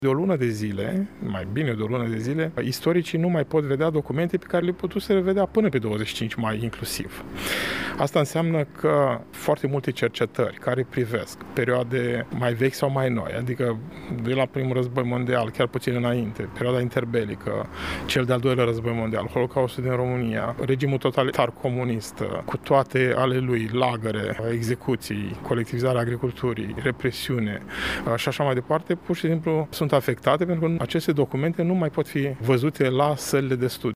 Institutul de Istorie „A.D. Xenopol” din Iași a găzduit astăzi dezbaterea intitulată „Documentele Secretului: Memoria istorică, libertatea de cercetare și politicile cu privire la arhivele publice în România.”